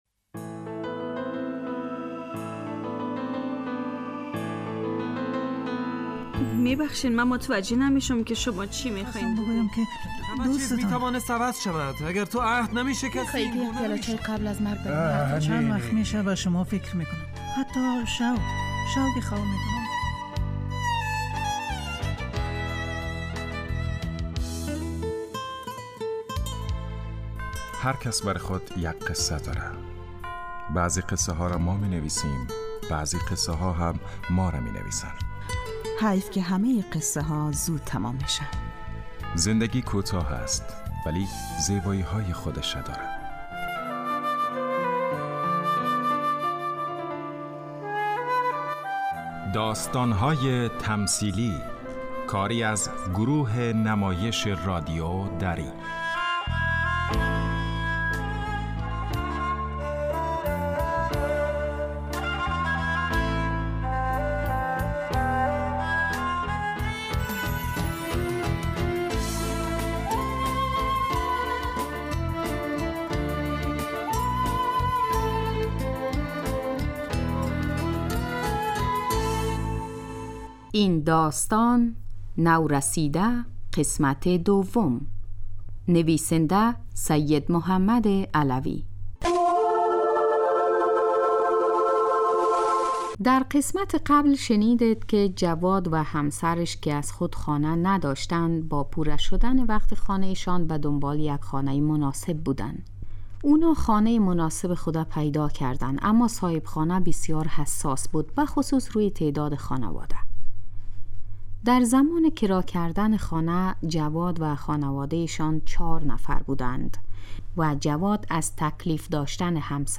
داستان تمثیلی